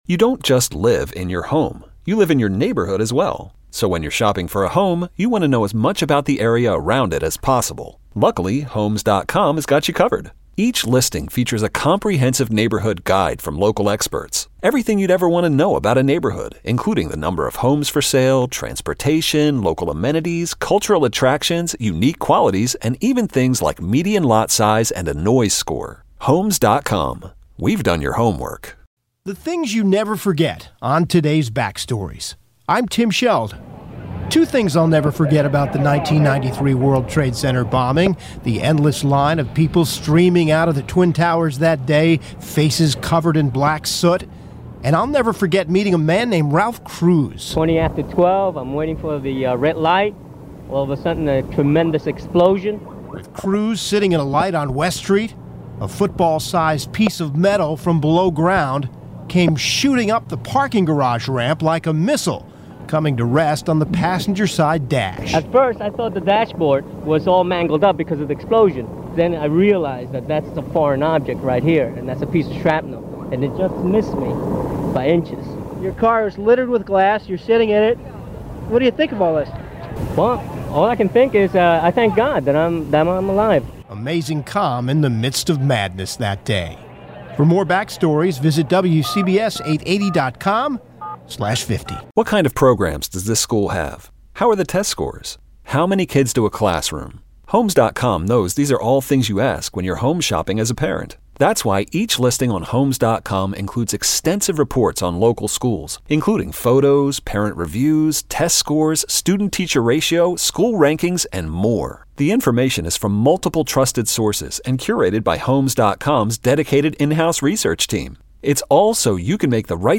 Listen to a man whose life was saved because of a few inches.